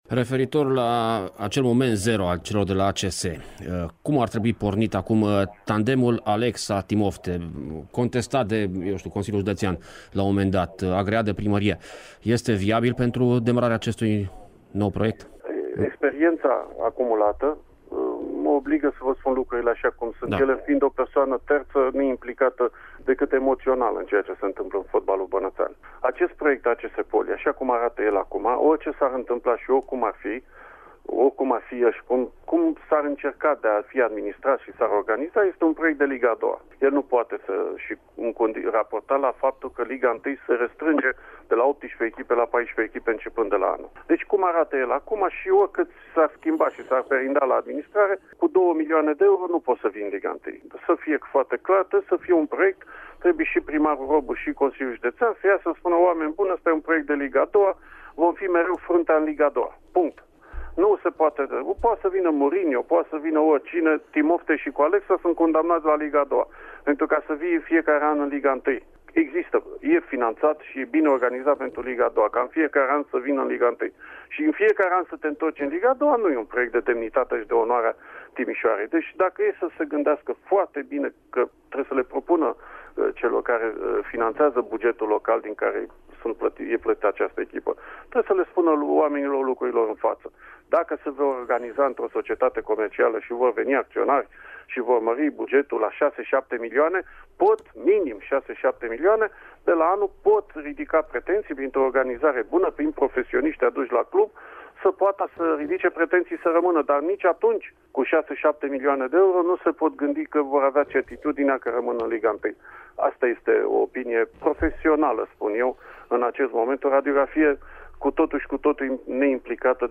Ascultaţi un fragment al dialogului